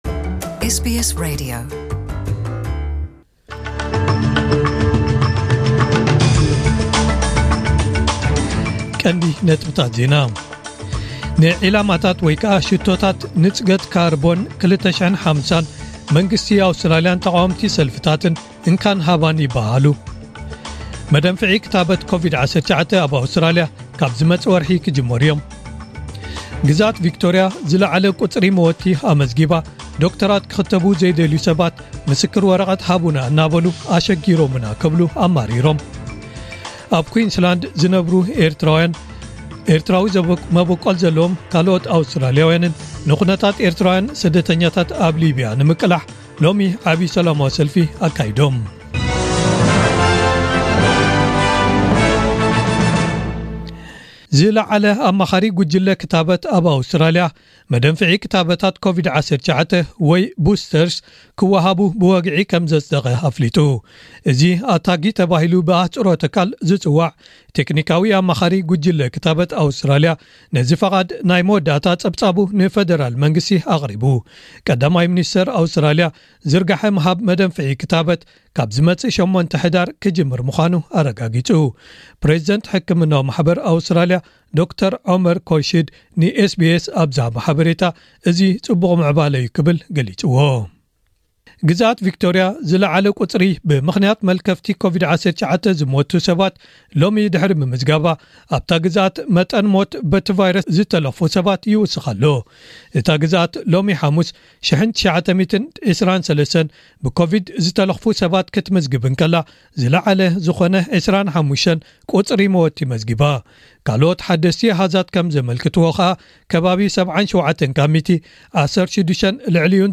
ዕለታዊ ዜና ኤስቢኤስ ትግርኛ